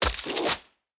Free Fantasy SFX Pack
Ice Throw 2.ogg